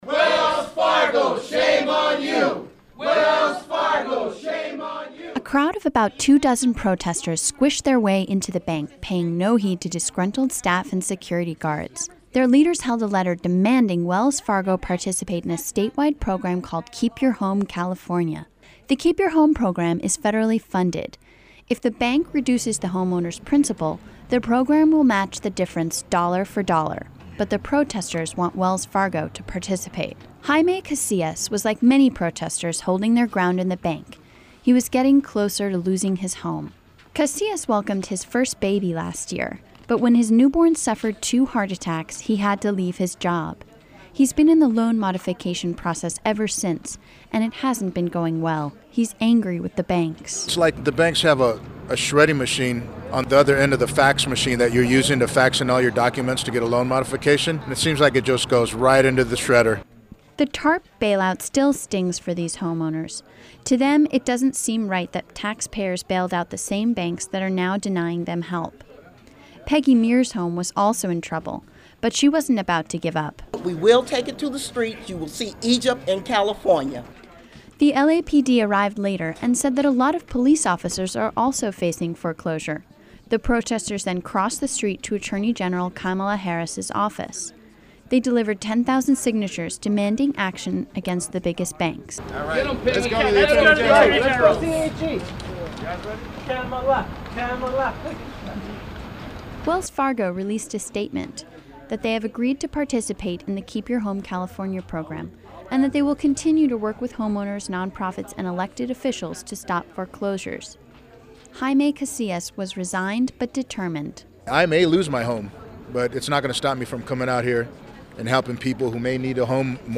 A crowd of about two dozen protesters squished their way into the bank, paying no heed to disgruntled staff and security guards. Their leaders held a letter demanding Wells Fargo participate in a state-wide program called "Keep Your Home California."